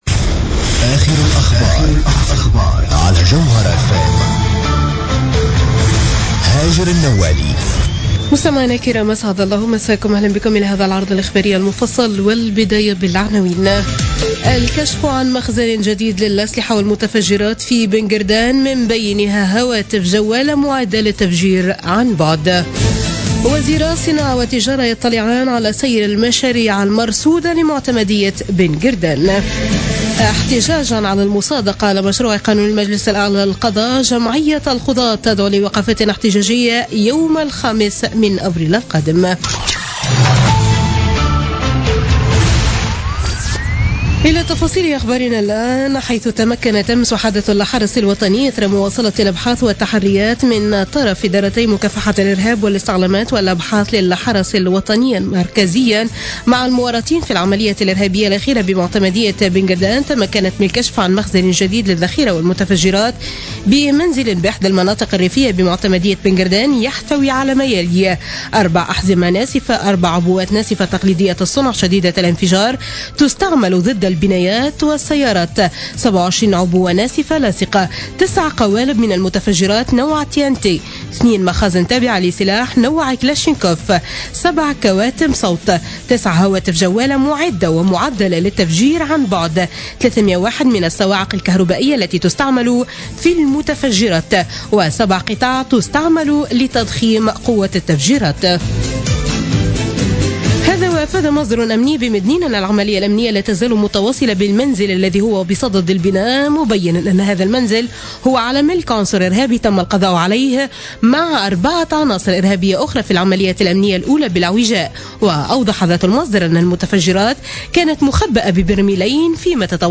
نشرة أخبار منتصف الليل ليوم الأحد 27 مارس 2016